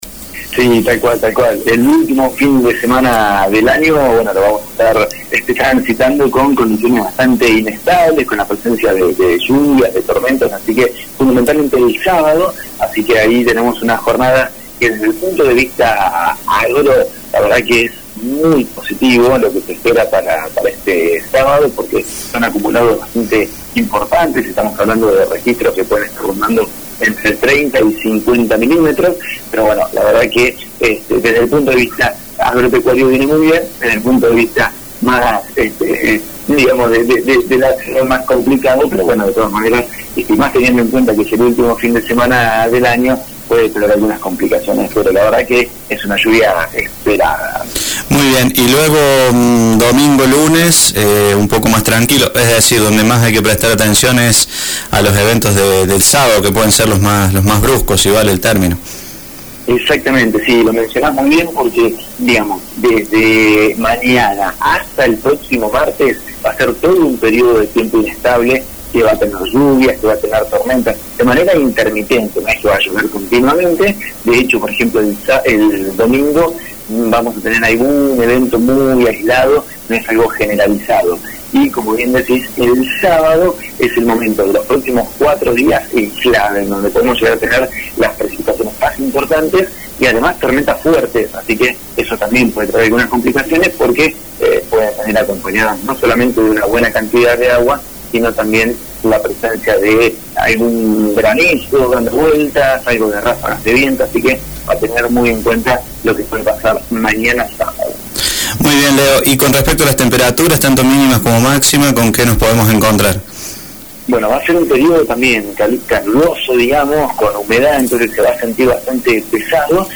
El pronostico del tiempo para el fin de semana